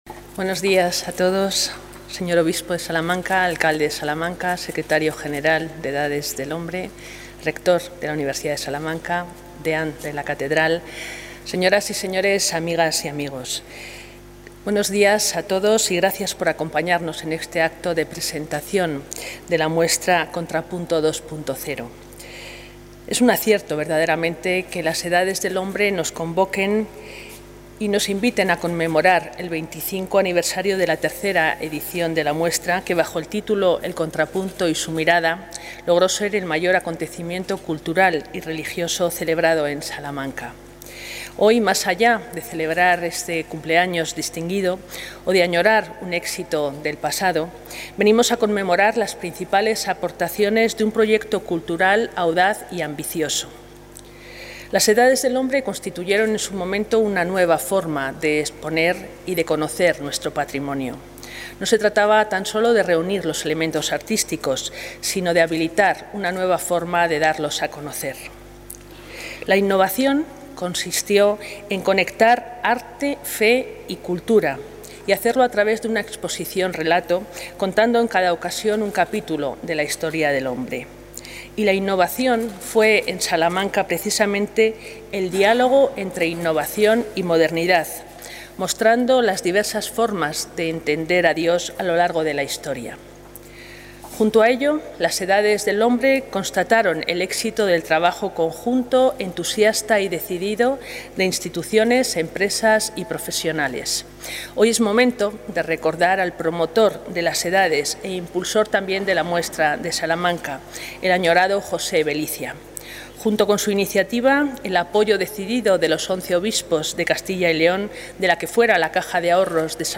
Consejera de Cultura y Turismo.